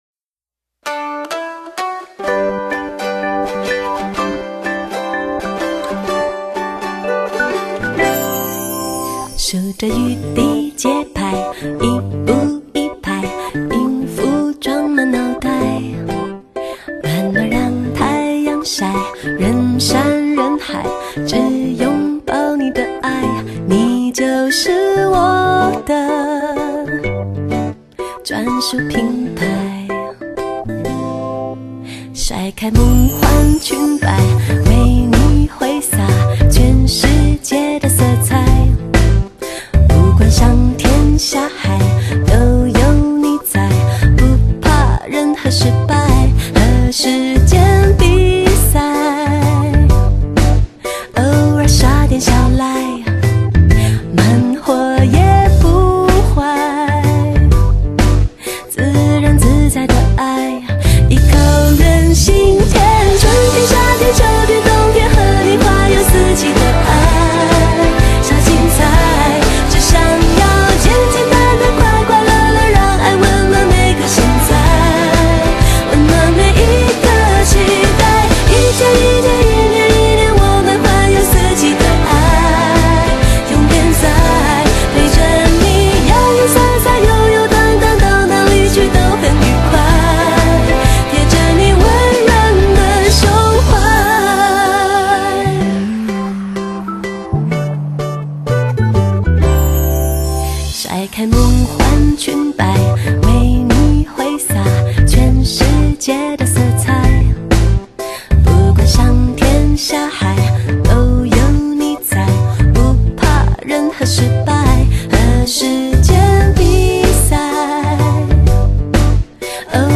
細膩的唱腔 總是展現一次比一次深刻的愛情深度